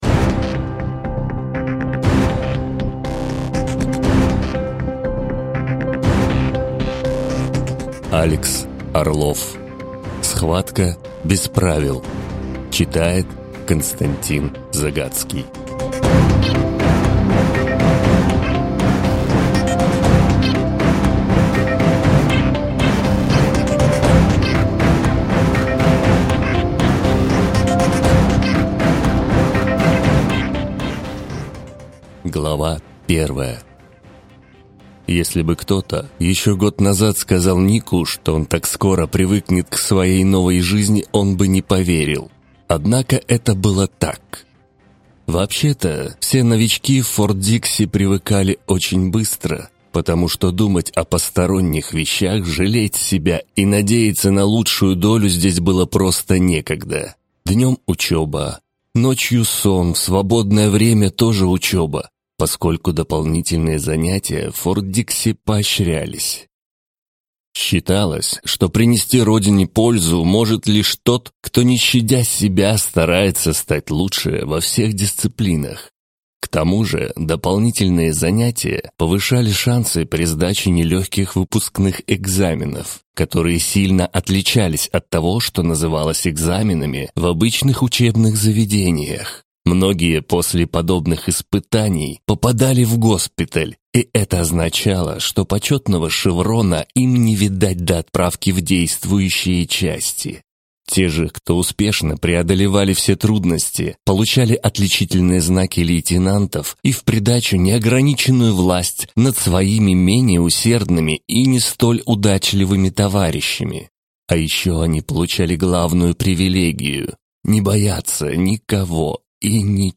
Аудиокнига Схватка без правил | Библиотека аудиокниг